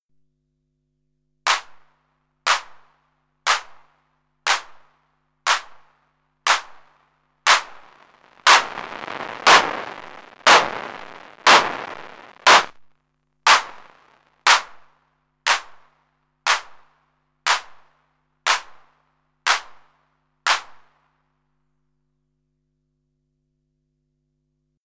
Also, compared to the sample, my noise seems kind of scratchy/distorted:
Yocto_Clap_scratchy_noise.mp3